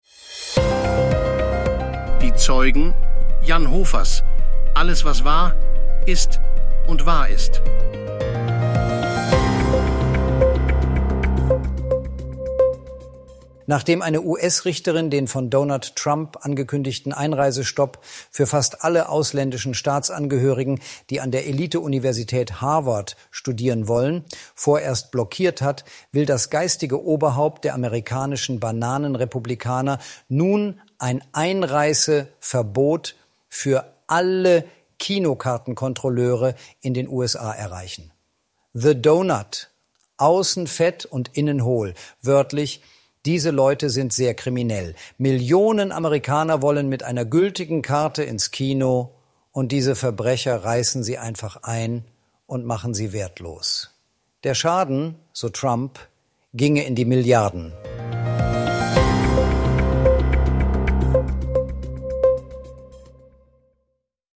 (100% KI-ssel)